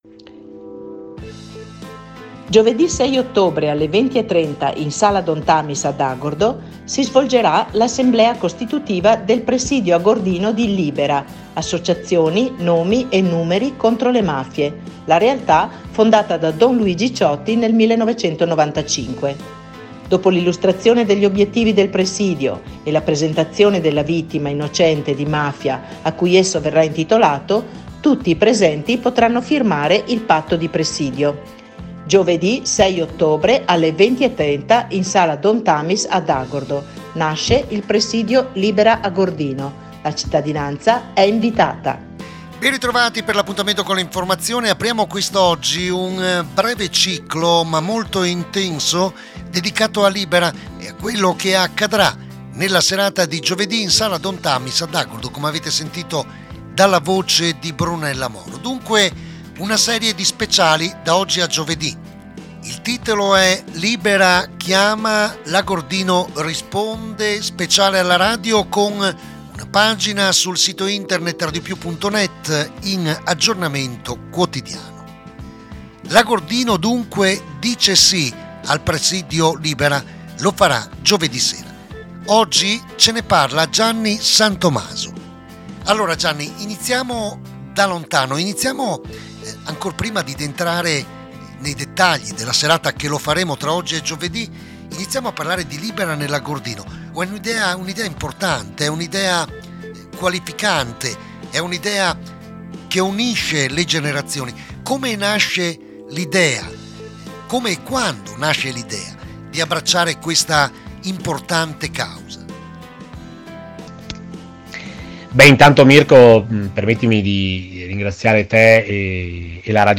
Dallo spazio “reporter” delle 10.30 e 1900.